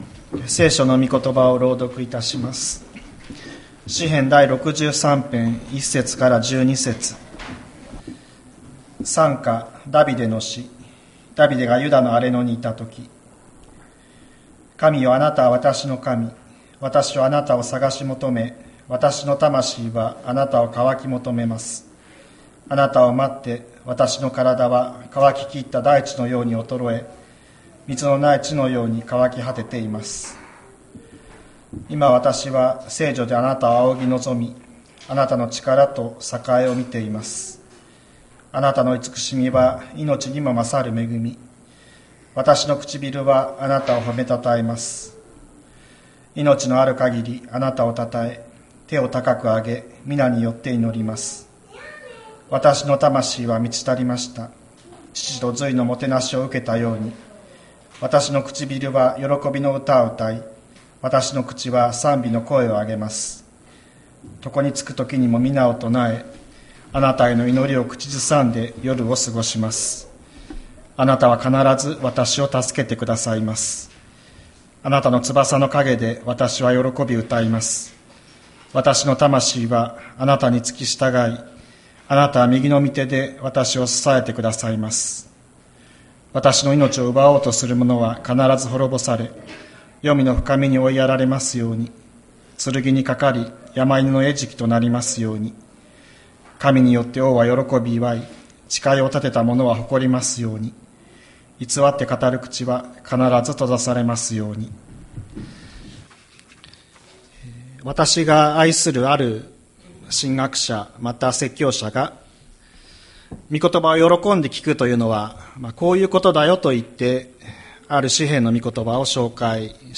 千里山教会 2025年05月04日の礼拝メッセージ。